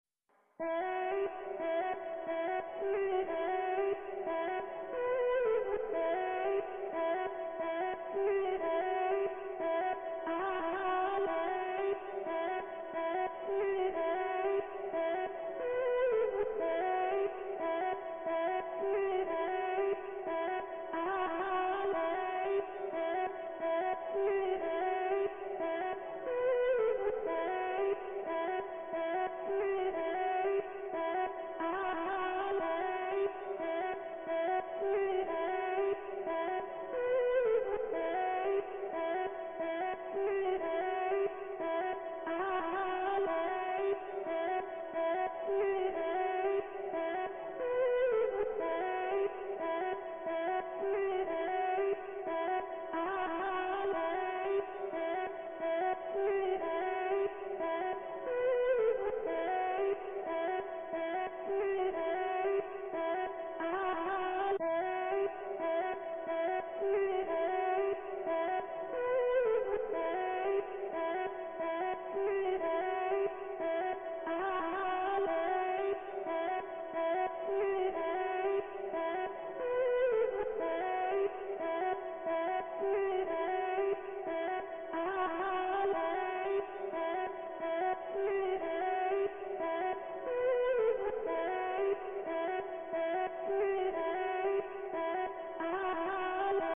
Voc FX (Version)